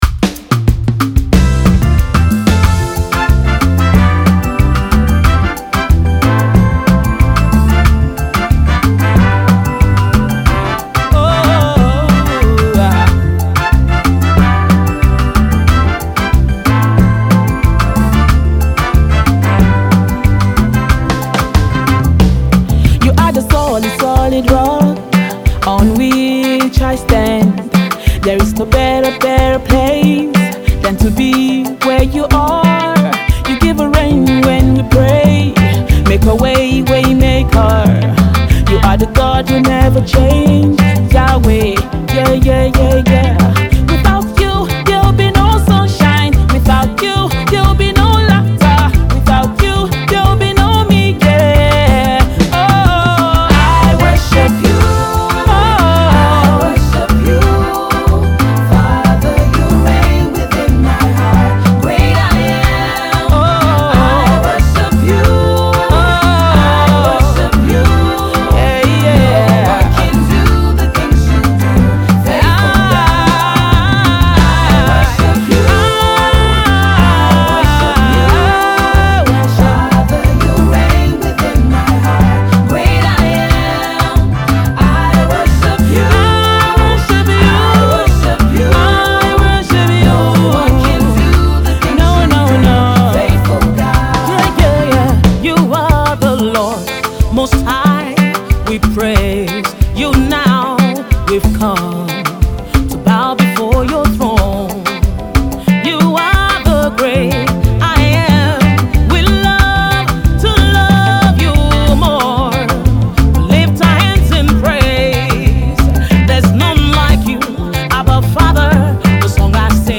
Sensational Gospel music star and angelic sound creator